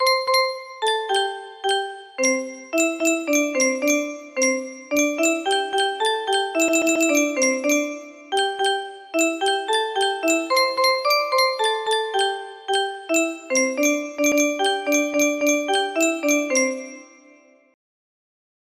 yukino shingun music box melody